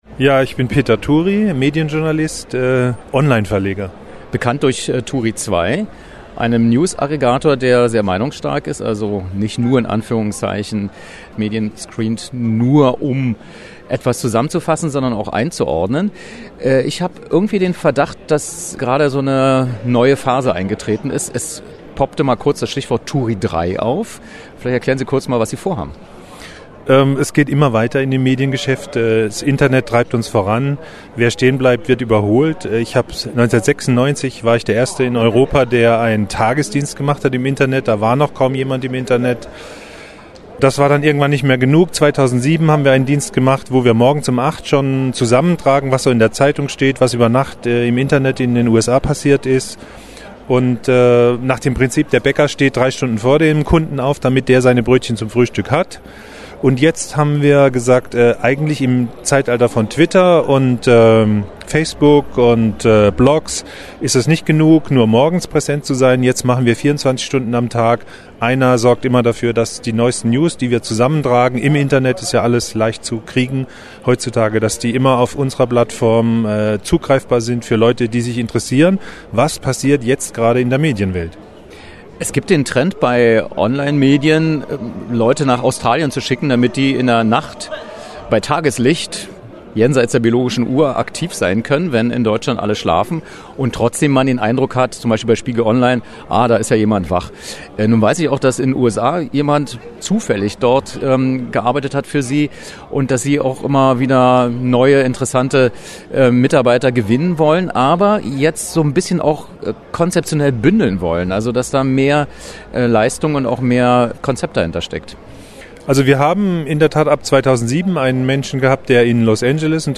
Was: Interview zur Neuausrichtung von turi2
Wo: München